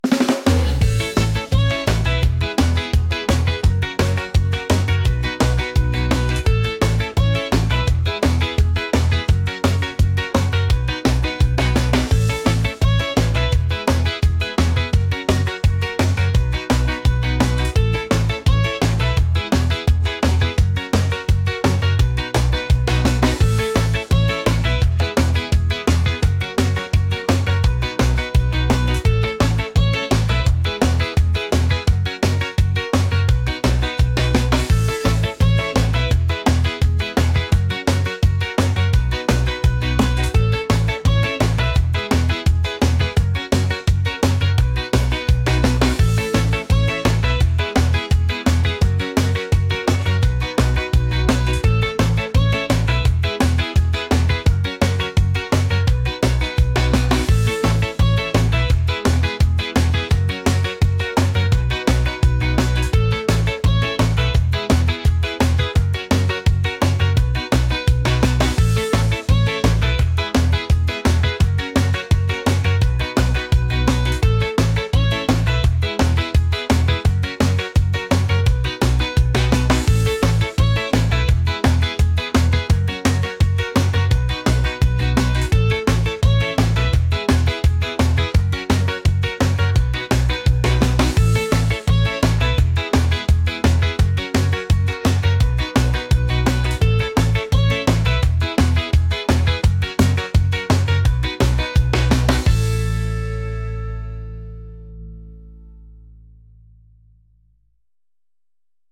catchy | upbeat | reggae